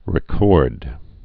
(rĭ-kôrd)